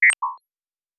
pgs/Assets/Audio/Sci-Fi Sounds/Interface/Digital Click 09.wav at master
Digital Click 09.wav